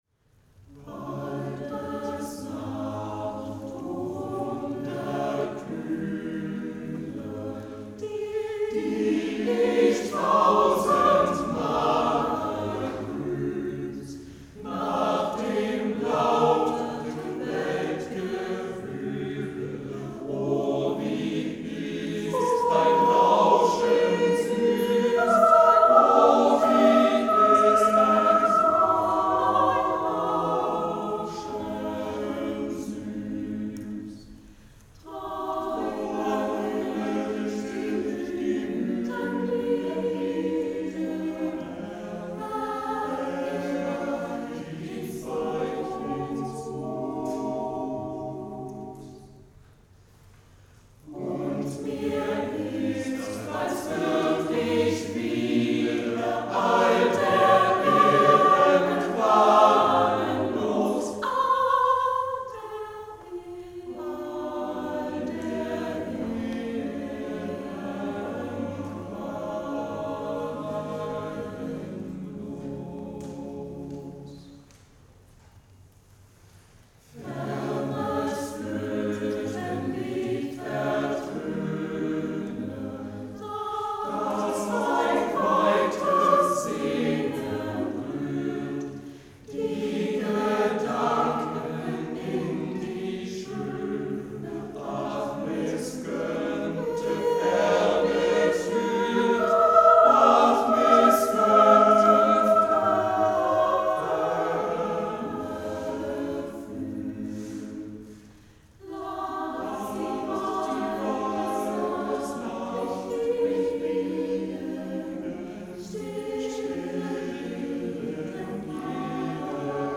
Hörproben vom hardChor ELLA